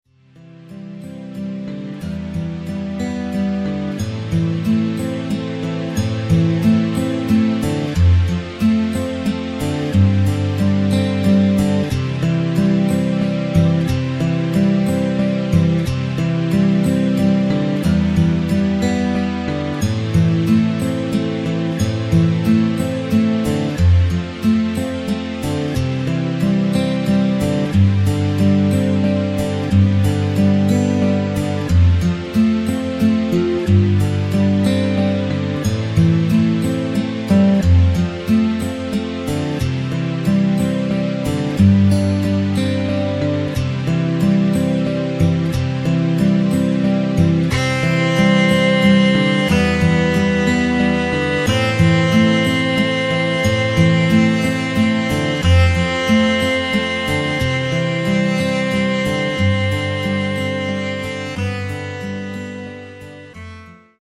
Takt: 6/8 Tempo: 91.00 Tonart: C
mp3 Playback Demo